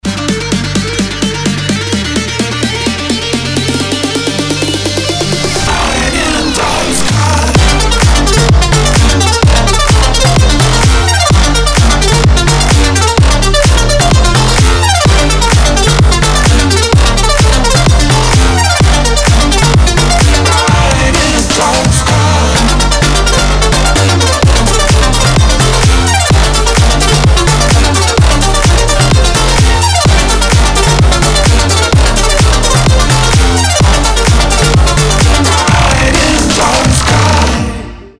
• Качество: 320, Stereo
громкие
жесткие
мощные
Electronic
EDM
Bass
Стиль: future house